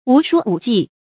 梧鼠五技 注音： ㄨˊ ㄕㄨˇ ㄨˇ ㄐㄧˋ 讀音讀法： 意思解釋： 比喻才能雖多不精。